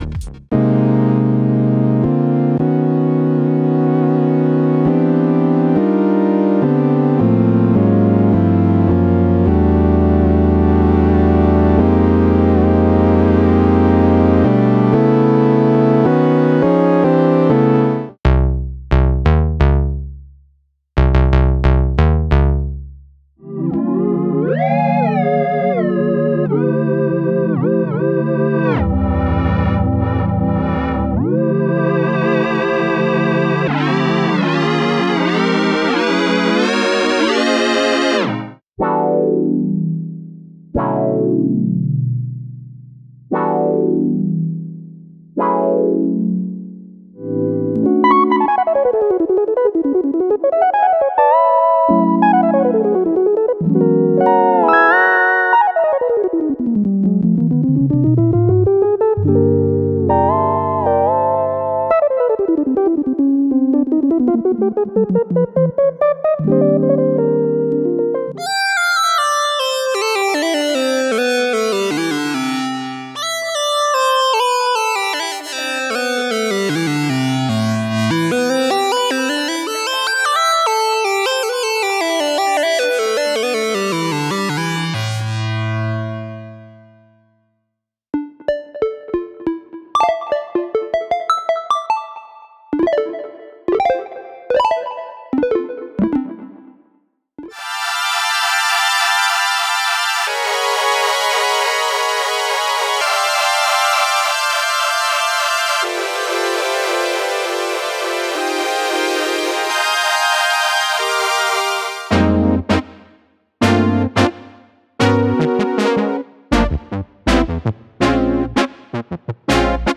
49 presets ranging from punchy basses to keyboard sounds and FX for OB-Xd.